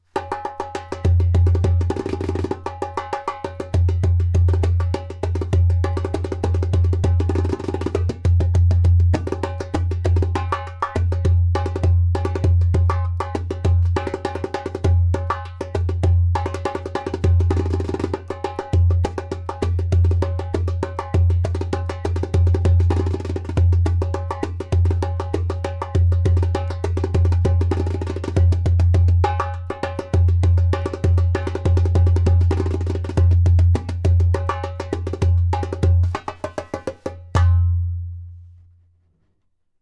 • Strong and super easy to produce clay kik (click) sound
• Beautiful harmonic overtones.
• Skin: Dotted goat skin